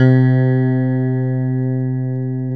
NICE E PNO.wav